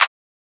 milli clap.wav